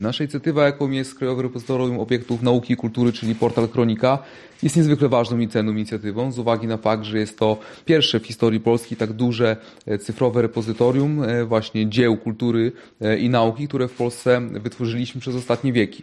Sekretarz Stanu w Ministerstwie Cyfryzacji, Adam Andruszkiewicz dodał, że warto skierować swoją uwagę na wspomniany portal: